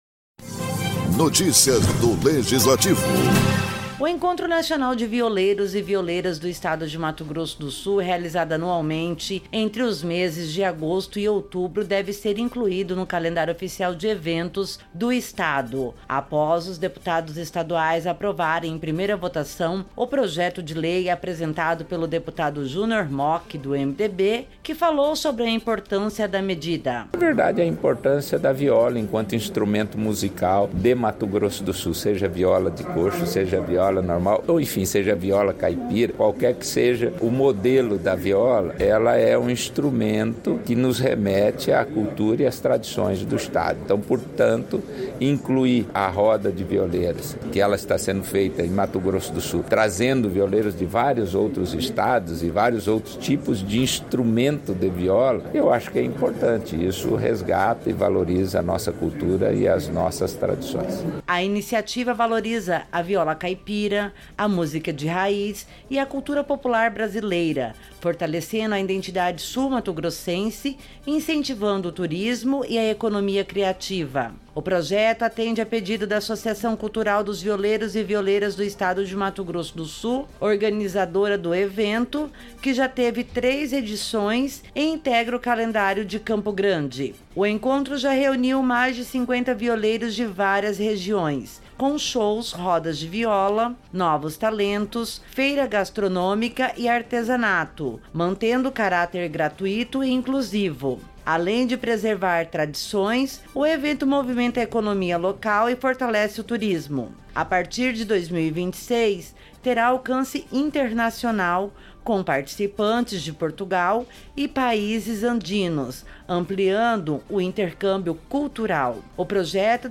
Produção e Locução